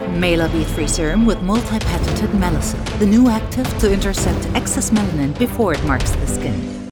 Female
Approachable, Bright, Character, Confident, Conversational, Corporate, Engaging, Friendly, Natural, Reassuring, Smooth, Versatile, Warm
Microphone: Rode NT1
Audio equipment: Focusrite Scarlett audio interface, pop filter, soundproof cabin